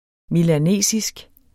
Udtale [ milaˈneˀsisg ]